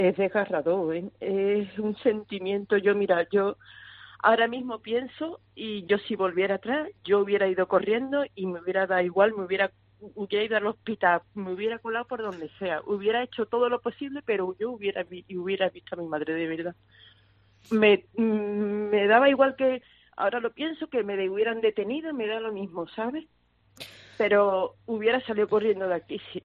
"Cada día duele más", el testimonio de la hija de una fallecida por coronavirus